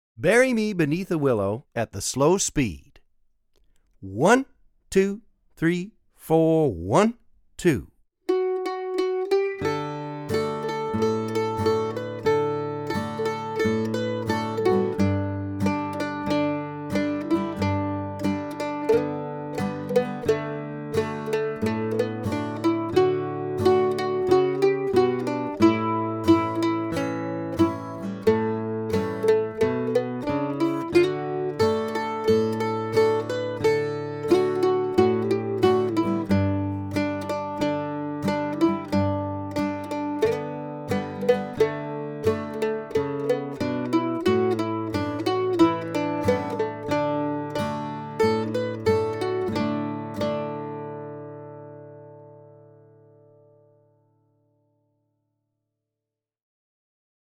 DIGITAL SHEET MUSIC - MANDOLIN SOLO
Online Audio (both slow and regular speed)